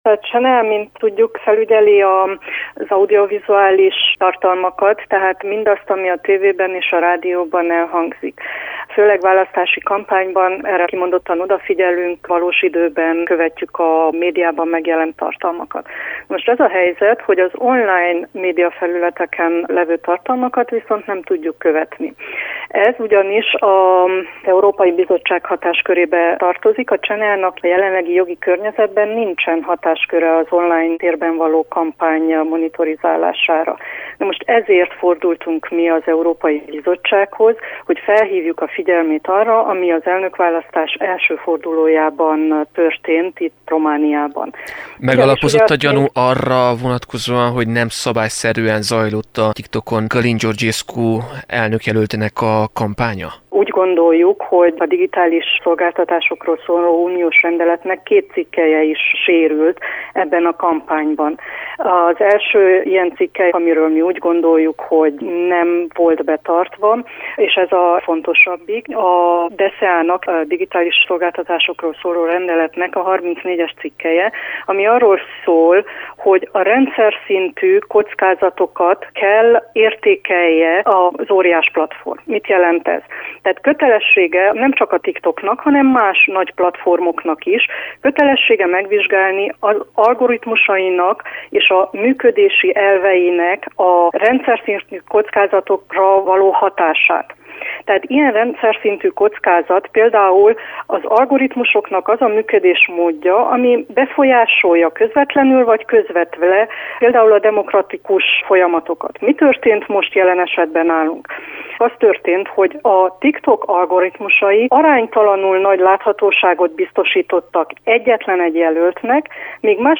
Borsos Orsolya CNA-tag válaszolt rádiónknak.
Az Európai Bizottsághoz intézett kérésről az Országos Audiovizuális Tanács tagját, Borsos Orsolyát kérdeztük.